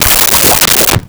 Swirl 03
Swirl 03.wav